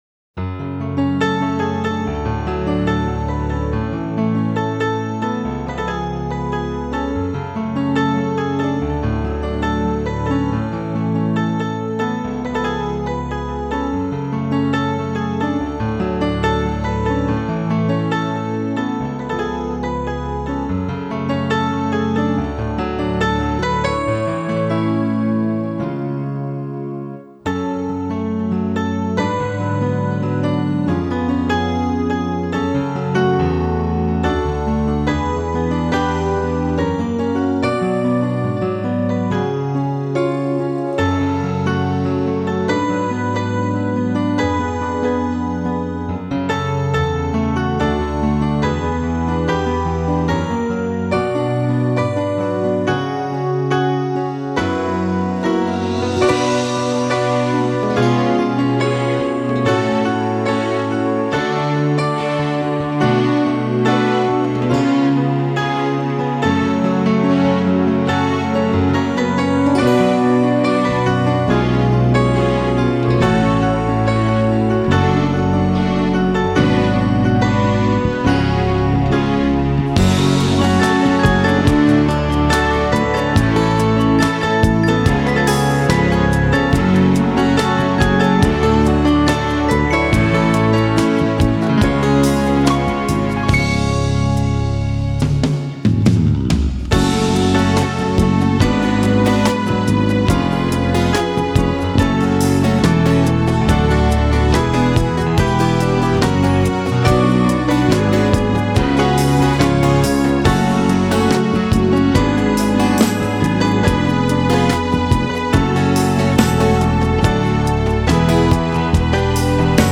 Этот инструментал целиком и в хорошем качестве